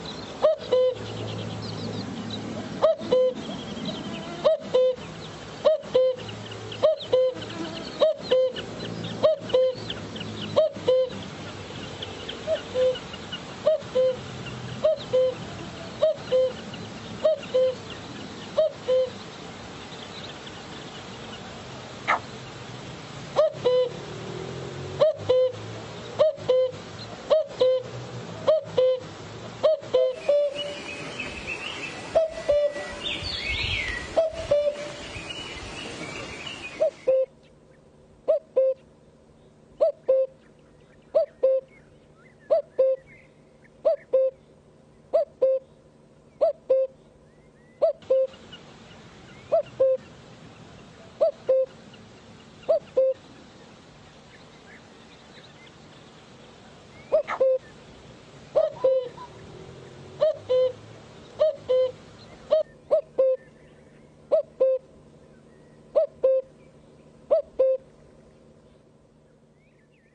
布谷鸟鸣叫声